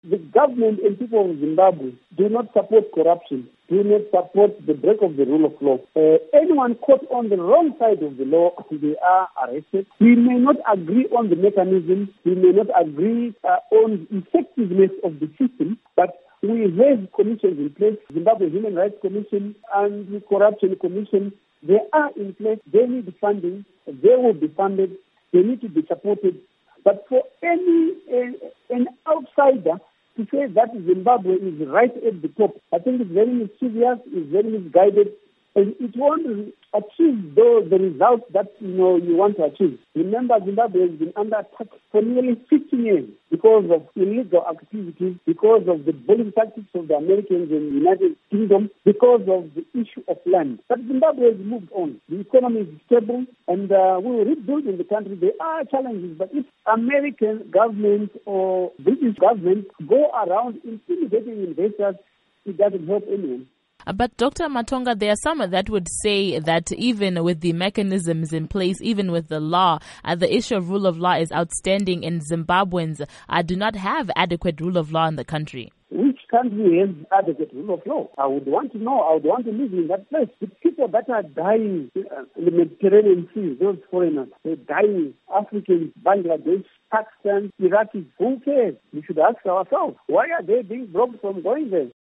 Interview With Bright Matonga